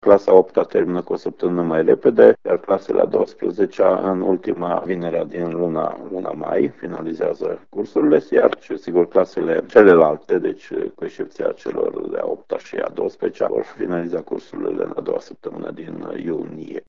Inspectorul școlar general Ștefan Someșan.